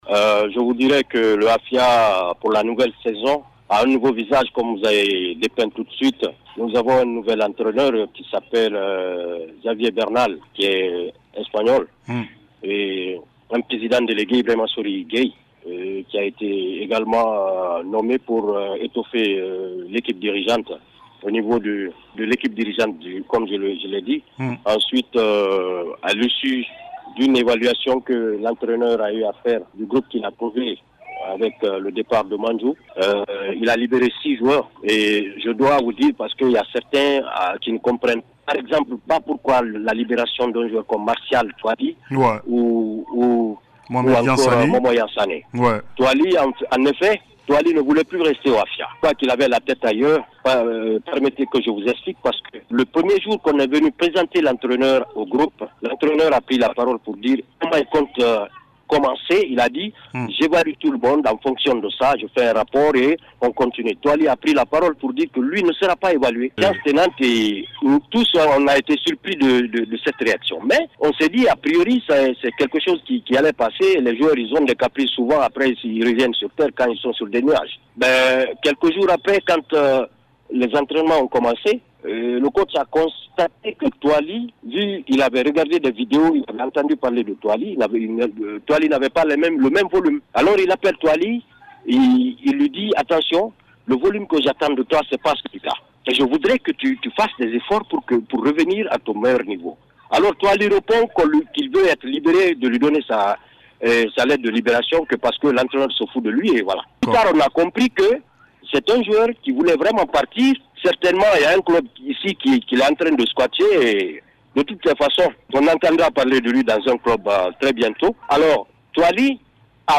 a été interrogé par Cis Radio dans l’émission Rond Central.